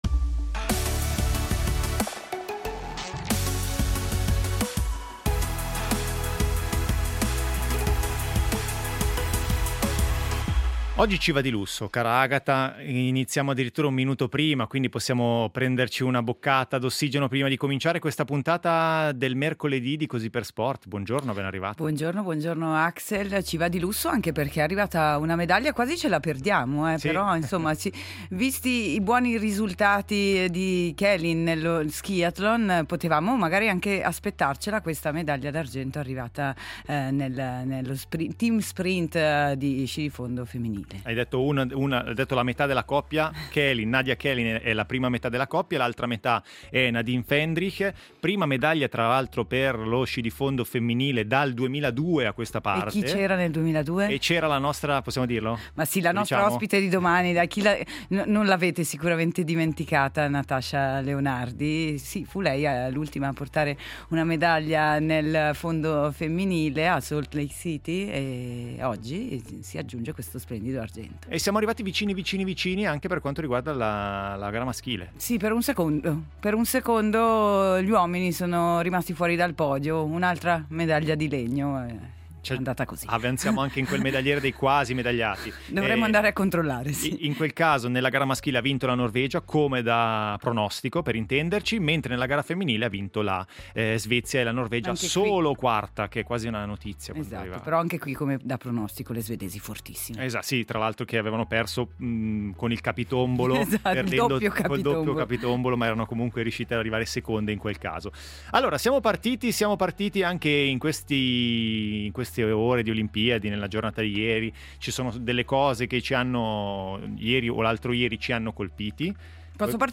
Tornano le Olimpiadi e torna Così per sport per raccontarvi minuto per minuto i Giochi di Milano-Cortina. I risultati, il medagliere, le voci degli inviati e le storie degli ospiti che hanno vissuto sulla propria pelle la rassegna a cinque cerchi, ma anche i dietro le quinte e le curiosità delle varie discipline per immergerci tutti insieme – sportivi e meno – nello spirito olimpico.